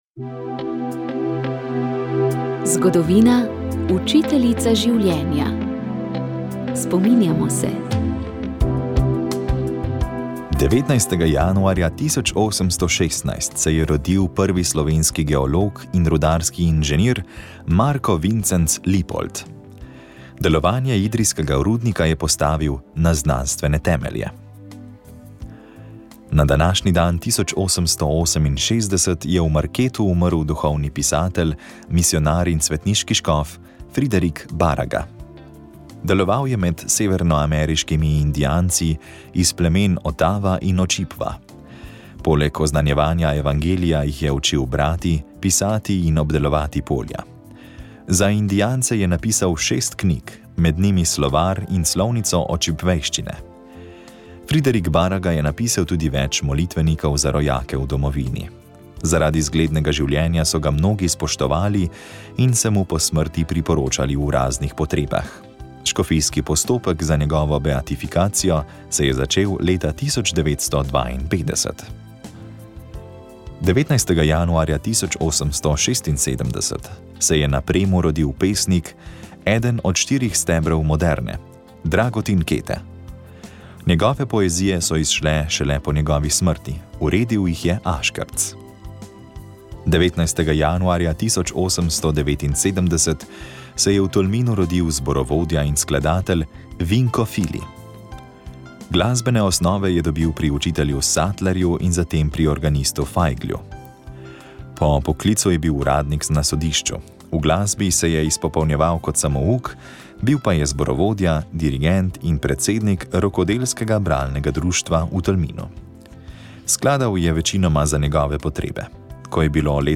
Duhovni nagovor